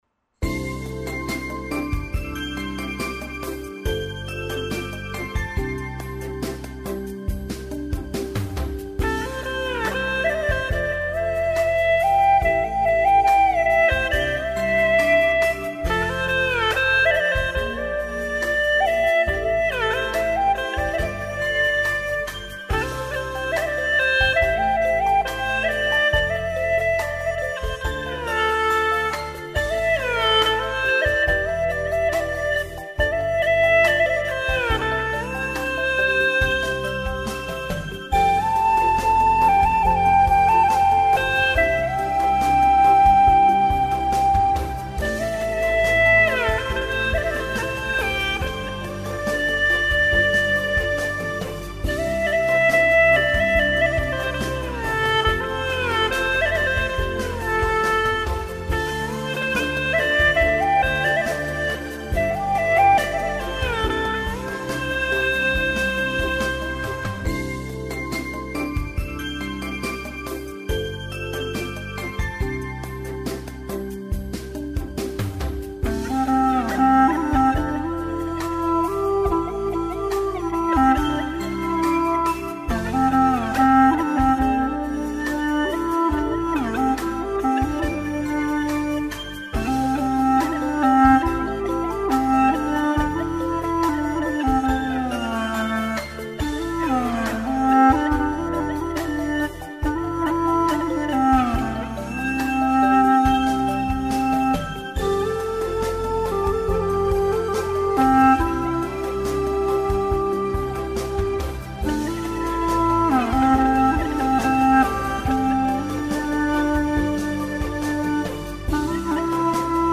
调式 : C 曲类 : 独奏
【大小C调】